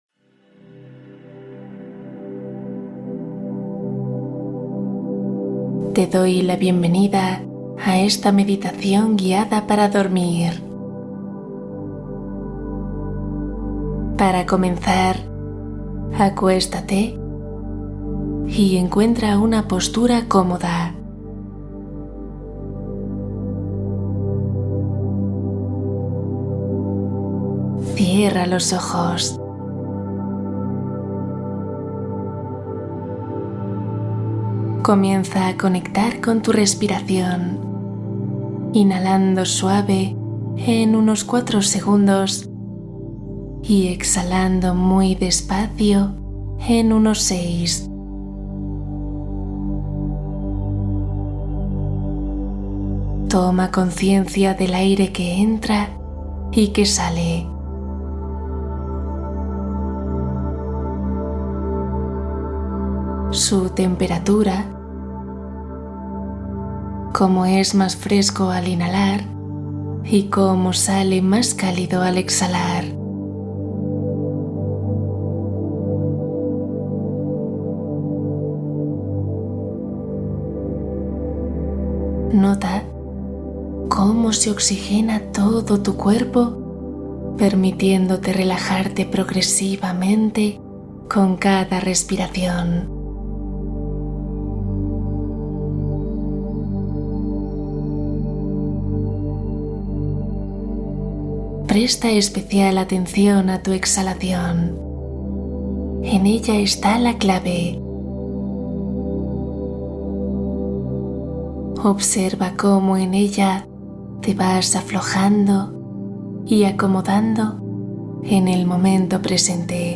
Paz nocturna ❤ Meditación para ansiedad e insomnio